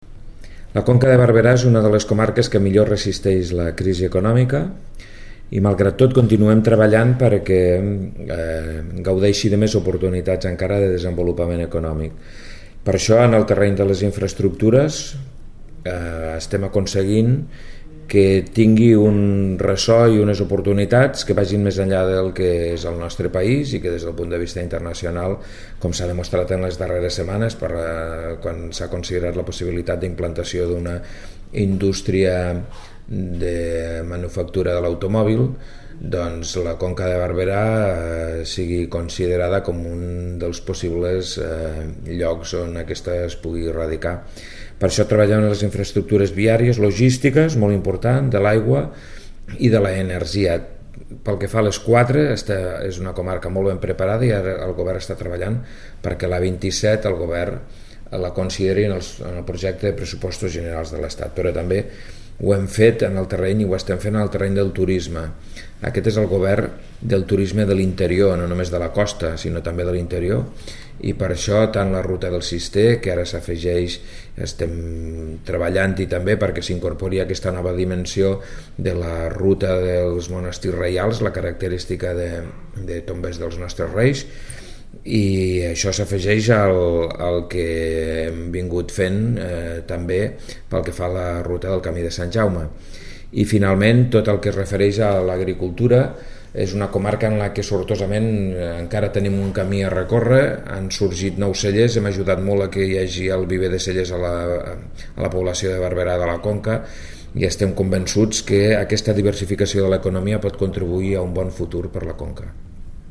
El delegat ha fet aquestes declaracions al Consell Comarcal de la Conca de Barberà, on aquest matí ha presidit el Consell de Direcció de l’Administració Territorial de la Generalitat al Camp de Tarragona, que ha comptat amb la participació del president de l’ens comarcal, David Rovira.
Tall de veu del delegat del Govern